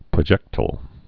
(prə-jĕktəl, -tīl)